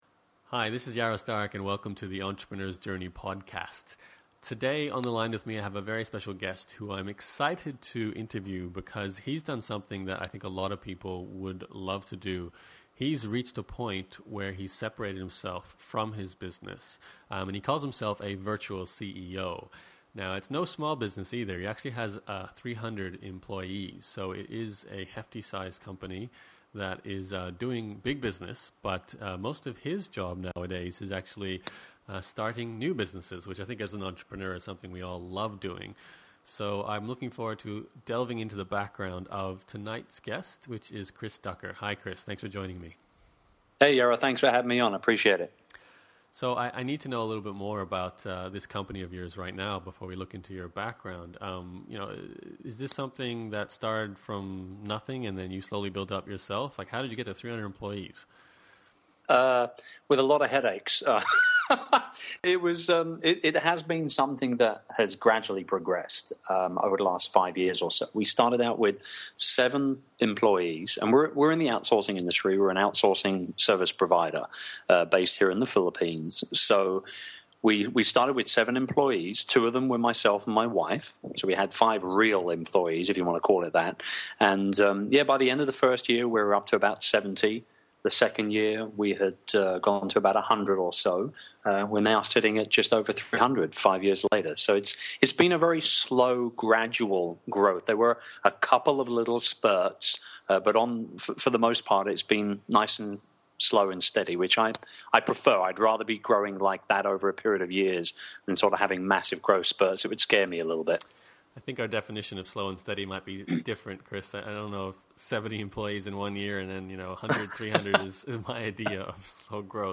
Starting a successful business is one thing. Removing yourself from it is another. In this interview